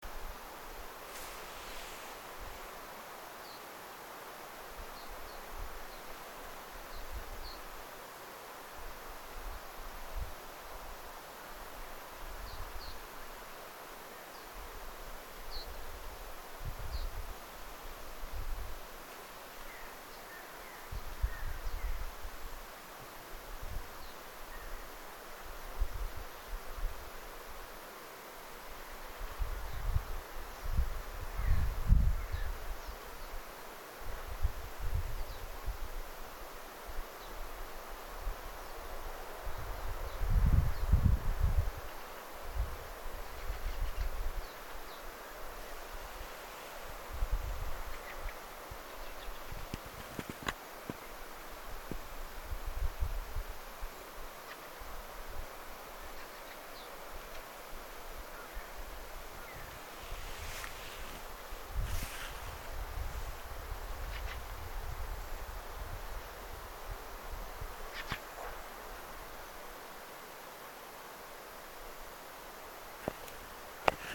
Elv-gulerle-bevegelse.mp3